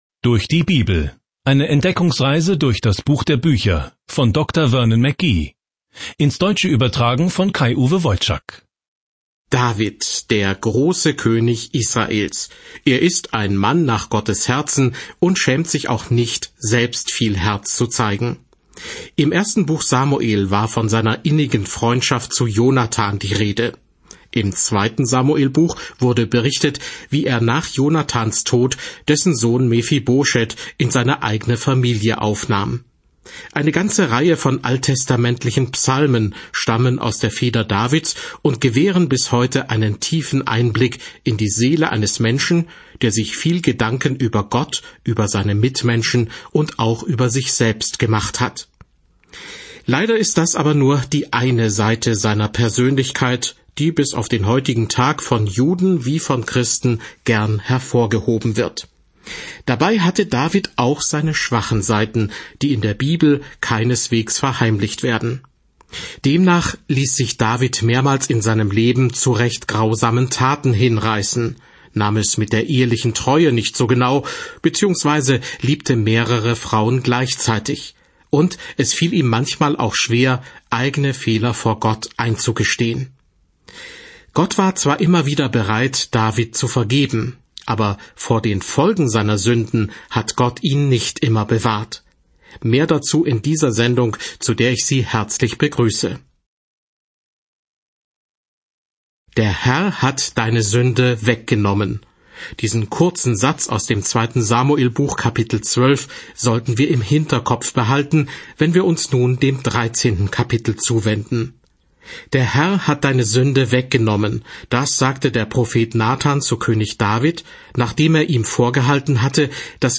Samuel 14 Tag 7 Diesen Leseplan beginnen Tag 9 Über diesen Leseplan Die Geschichte der Beziehung Israels zu Gott wird mit der Einführung von Propheten in die Liste der Beziehungen zwischen Gott und seinem Volk fortgesetzt. Reisen Sie täglich durch 2. Samuel, während Sie sich die Audiostudie anhören und ausgewählte Verse aus Gottes Wort lesen.